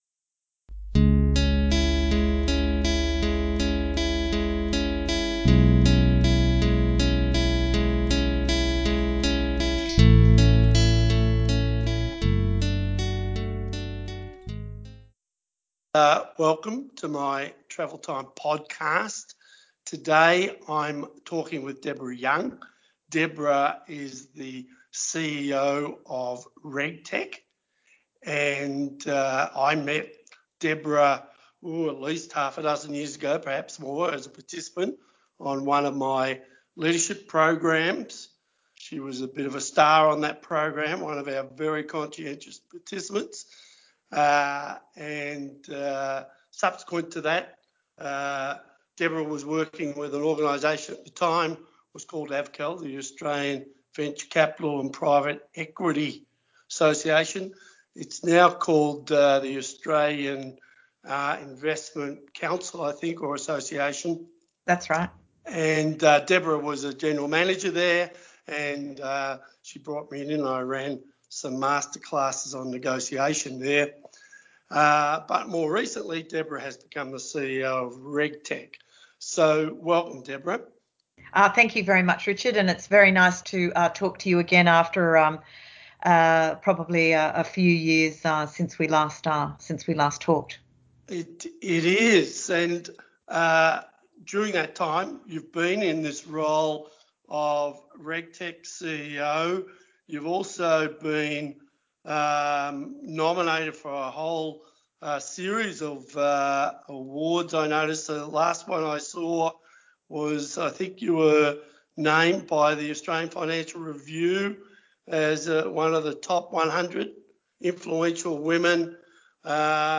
In this interview she talks about the remarkable growth of RegTech and how Australia has become a leader in developing technological innovations and solutions for the regulatory and compliance needs of firms and organisations.